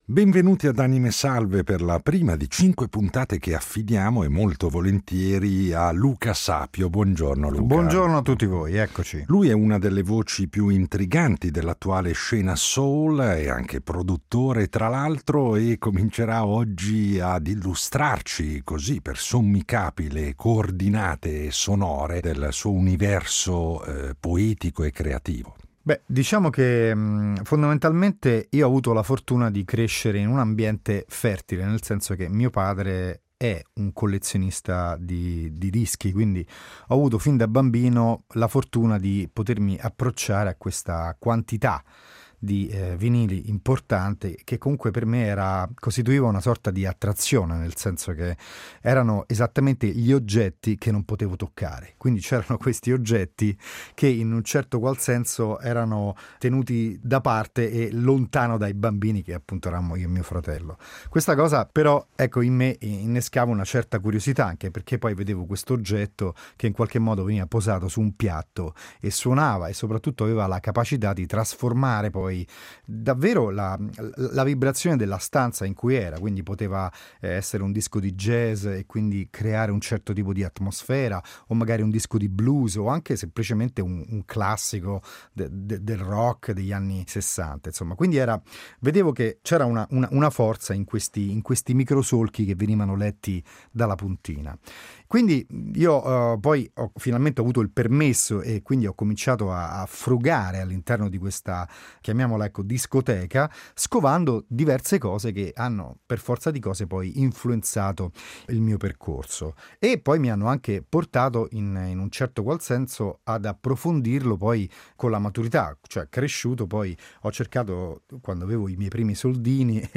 I suoi itinerari sonori per “Anime Salve” sono impreziositi da una serie di registrazioni inedite che chiudono ogni puntata, realizzate con la sua “ Italian Royal Family” nel suo studio di Roma.